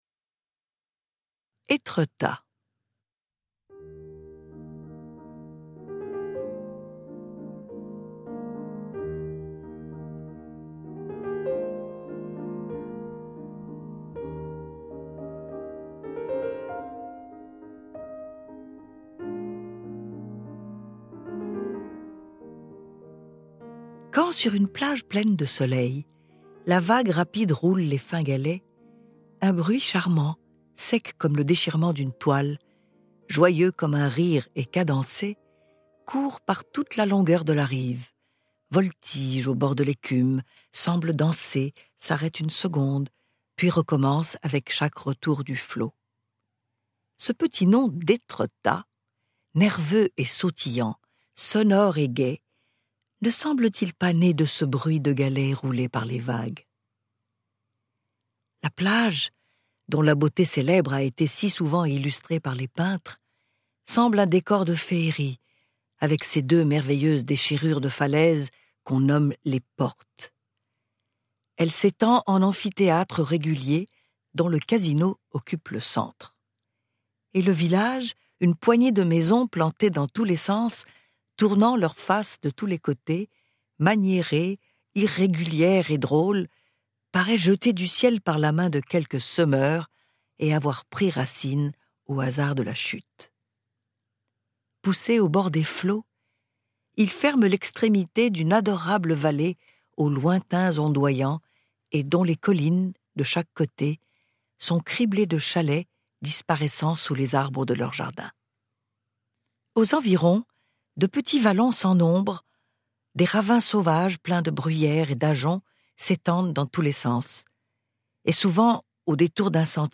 Diffusion distribution ebook et livre audio - Catalogue livres numériques
Lu par Macha Méril Durée : 0h58 12 , 00 € Ce livre est accessible aux handicaps Voir les informations d'accessibilité